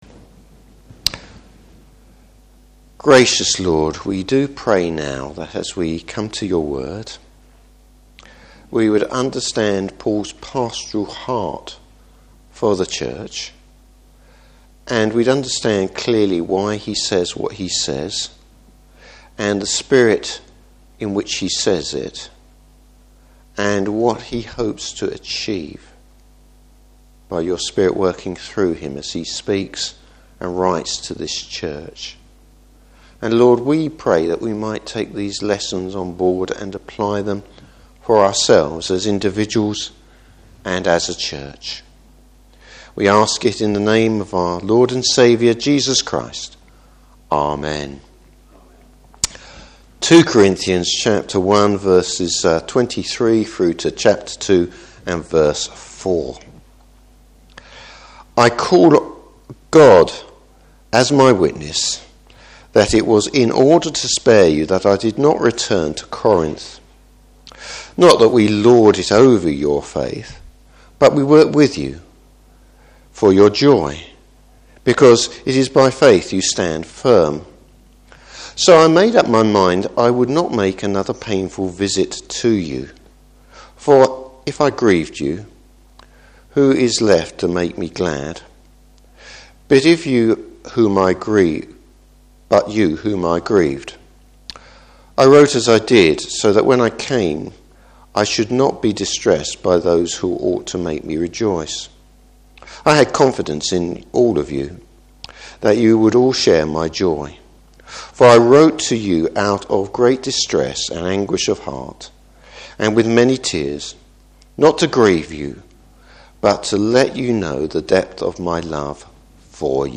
Service Type: Morning Service Paul’s approach to restoring fellowship.